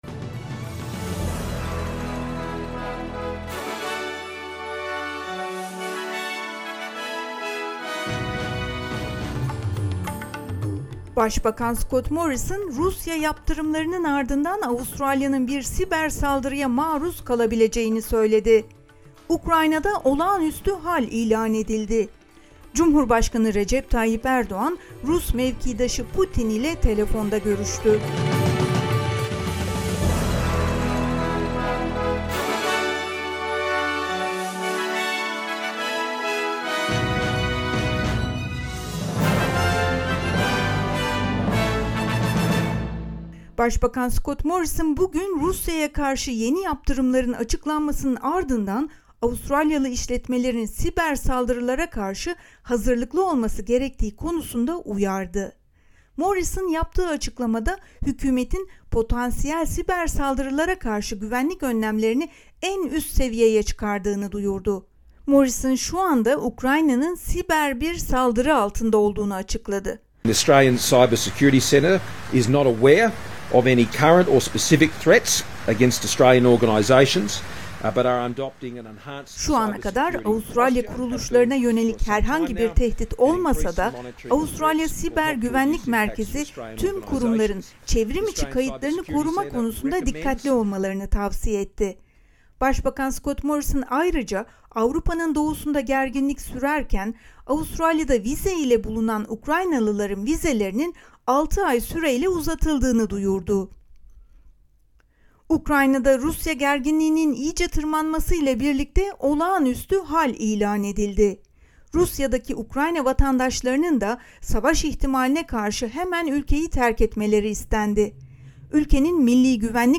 SBS Türkçe Haber Bülteni 24 Şubat